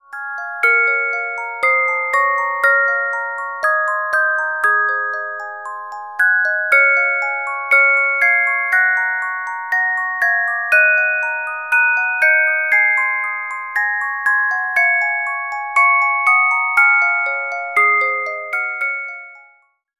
Music Box Melodies柔美音樂盒